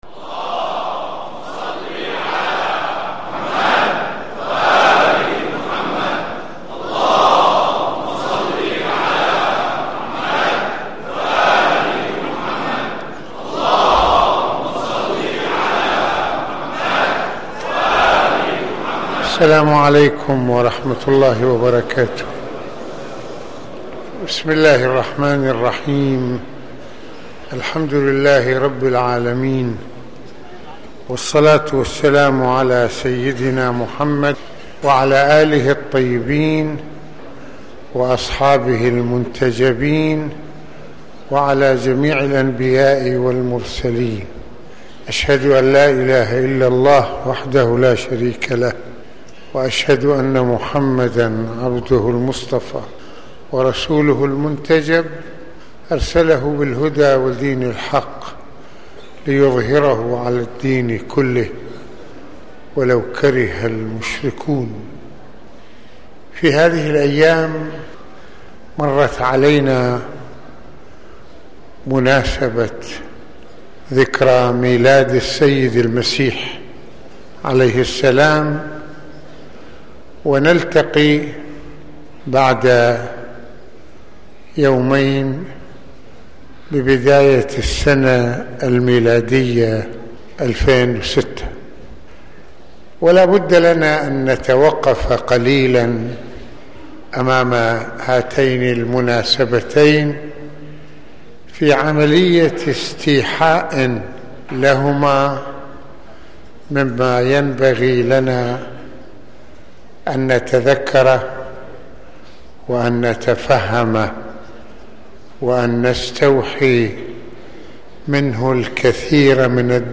خطبة الجمعة
مسجد الإمامين الحسنين (ع) - بيروت